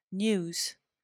wymowa:
enPR: n(y)o͞oz, IPA/n(j)uːz/, SAMPA/n(j)u:z/